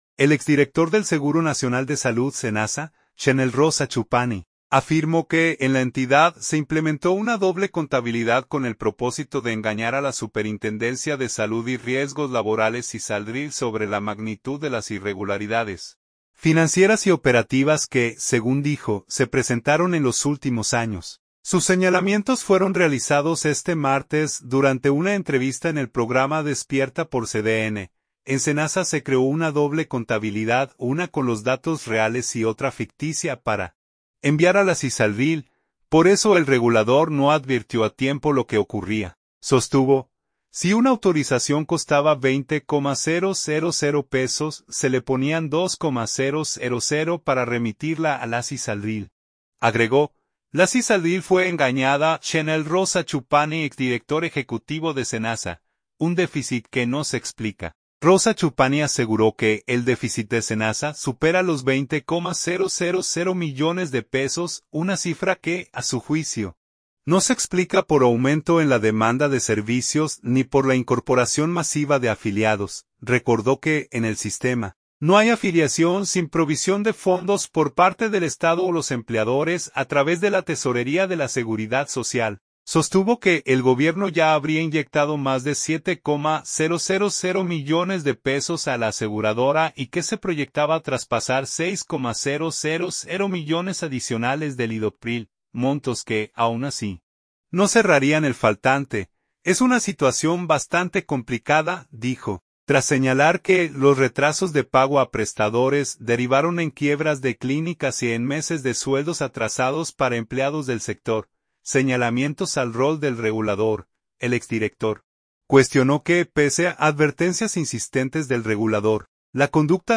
Sus señalamientos fueron realizados este martes durante una entrevista en el programa Despierta por CDN.